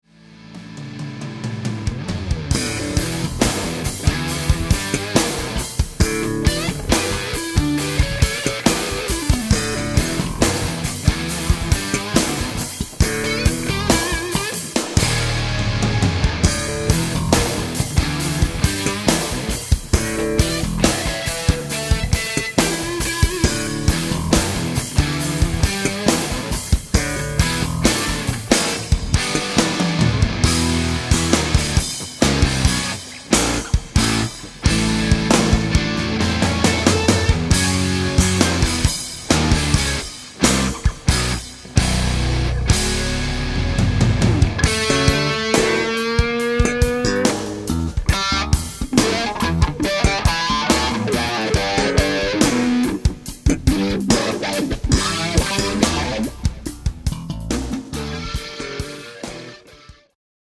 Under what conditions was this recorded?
Studiorecording